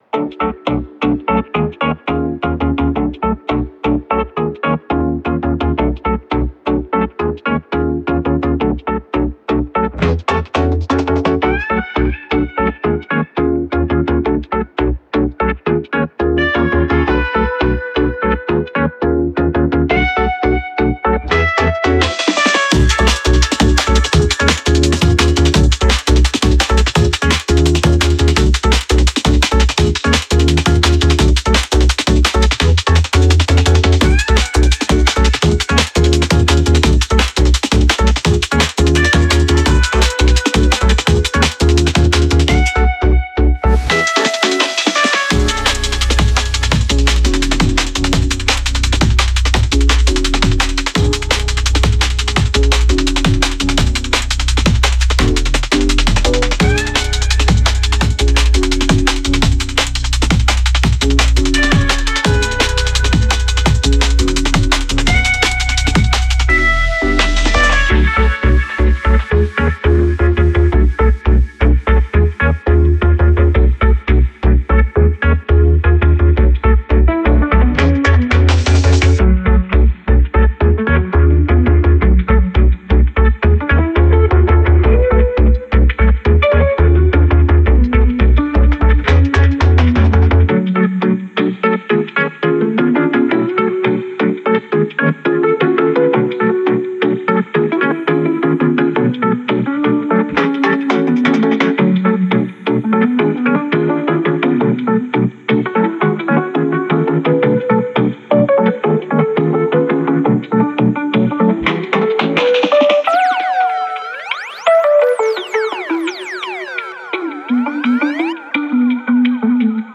featuring a world renowned trianglist